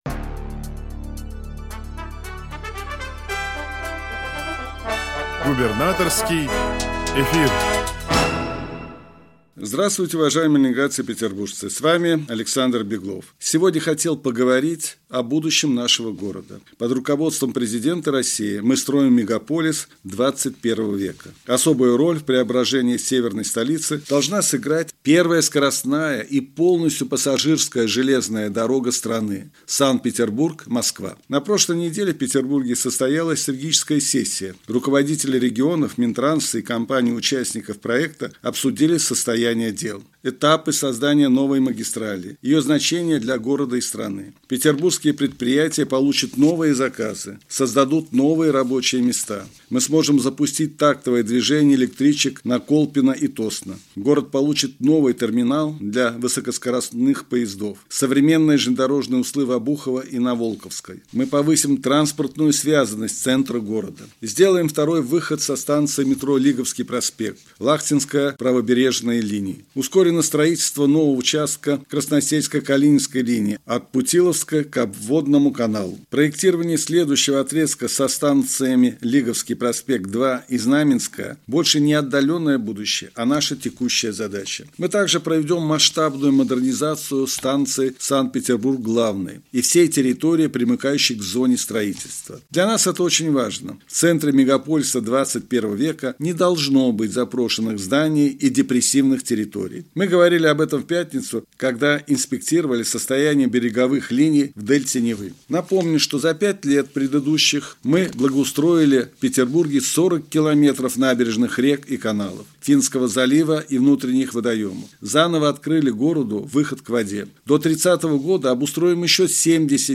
Радиообращение – 26 августа 2024 года